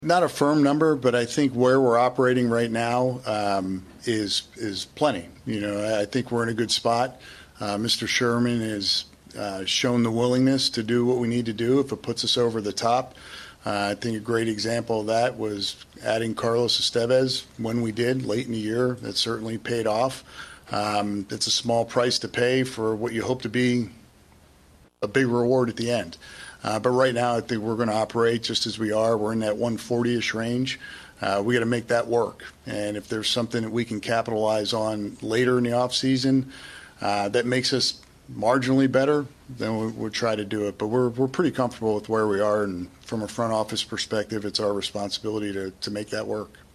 ROYALS HOLD END OF SEASON PRESS CONFERENCE